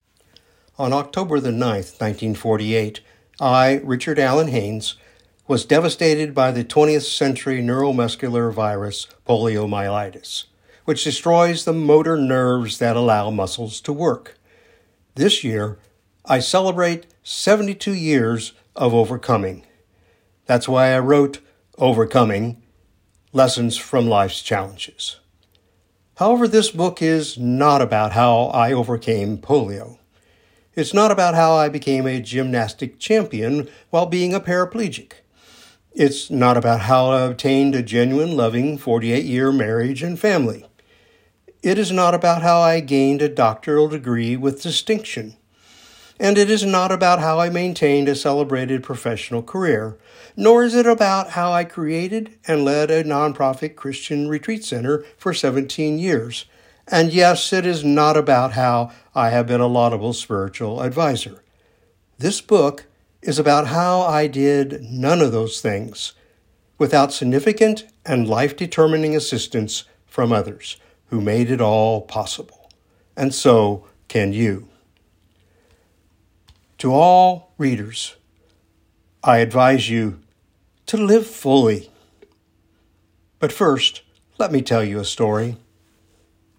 Preface-Audio-Reading1.wav